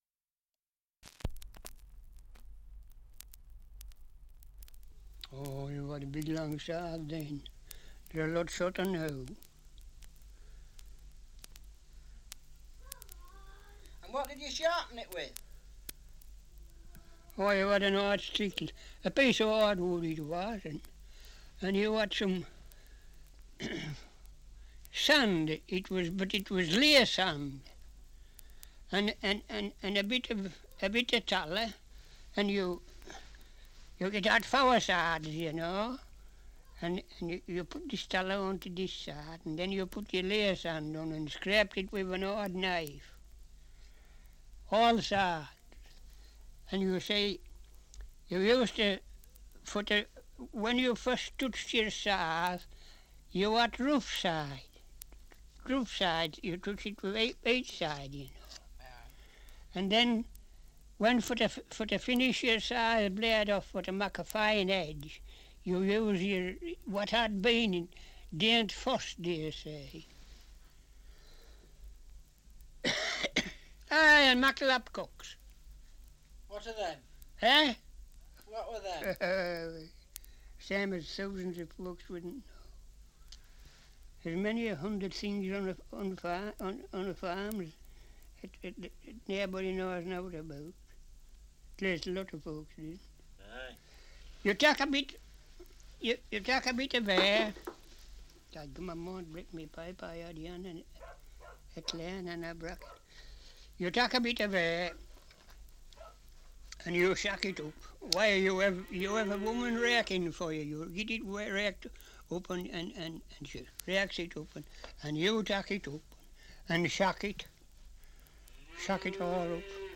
Survey of English Dialects recording in Borrowby, Yorkshire
78 r.p.m., cellulose nitrate on aluminium